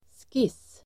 Uttal: [skis:]